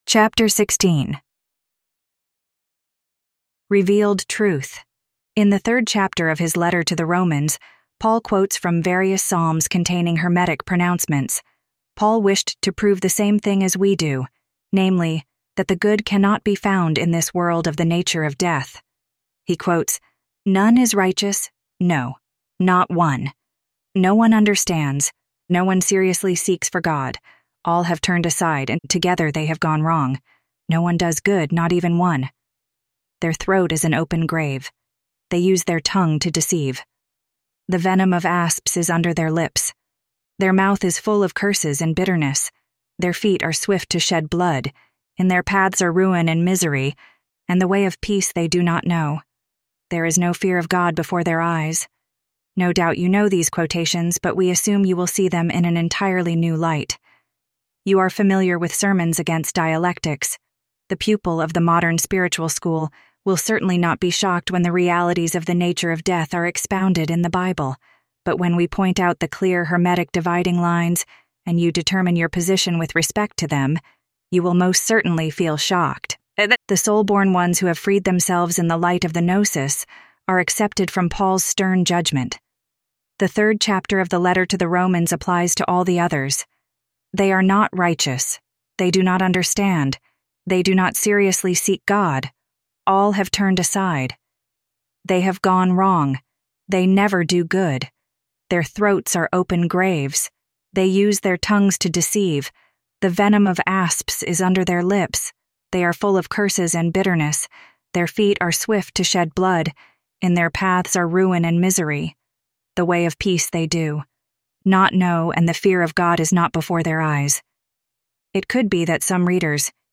Audio Books of the Golden Rosycross